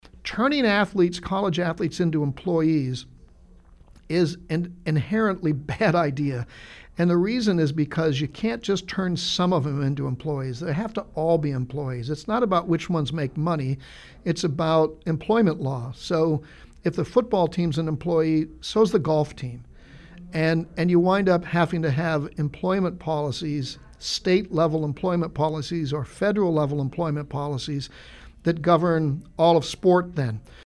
On Friday, NCAA president Mark Emmert joined Sports Scene and provided his insight into the changing realm of college sports.